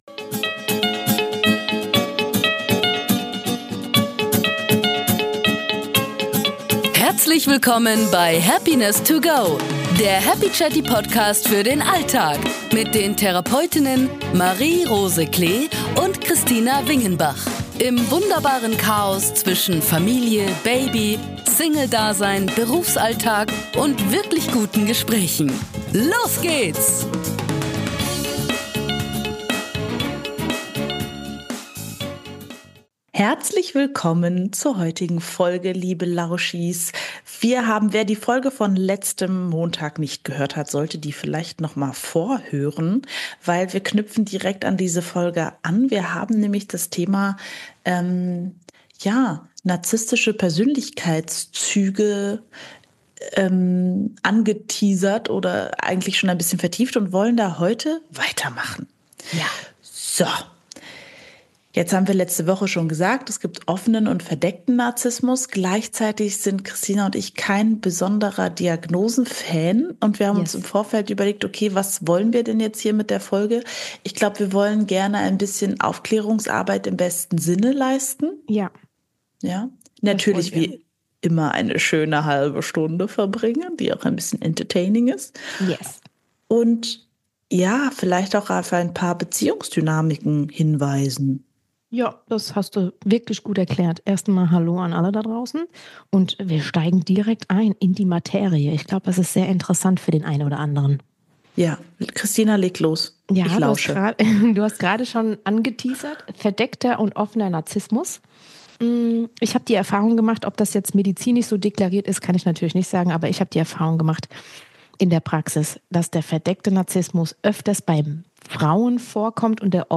In "Happiness to go" nehmen euch zwei Therapeutinnen mit auf eine Reise durch die Höhen und Tiefen des Alltags und der Therapie.